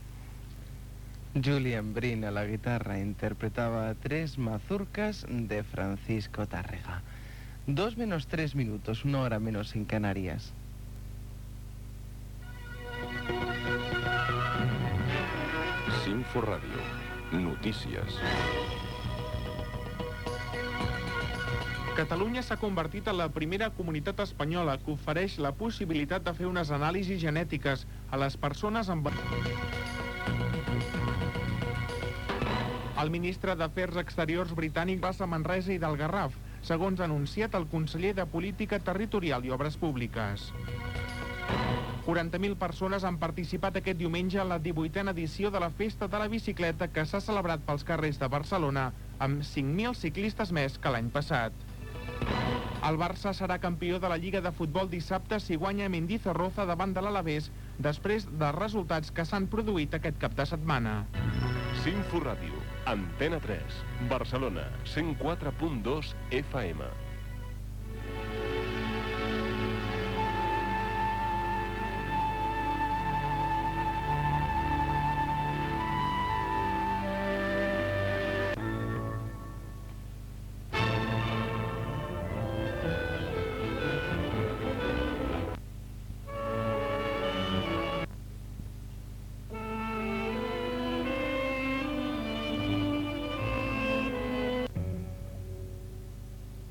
Presentació d'un tema musical, hora, careta de l'informatiu, festa de la bicicleta, lliga de futbol, indicatiu de l'emissora, música.
Informatiu
FM